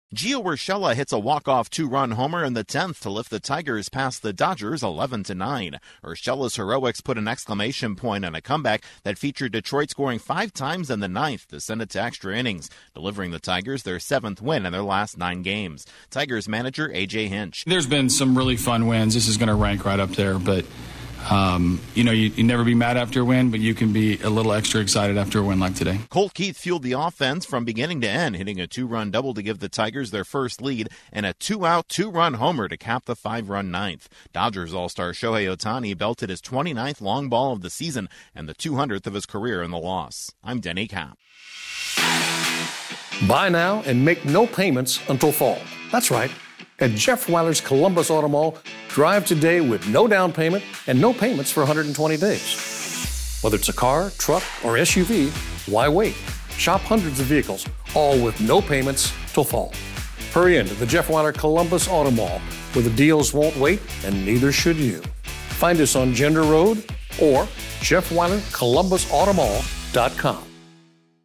The Tigers come from behind in dramatic fashion to stun the Dodgers in an extra inning. Correspondent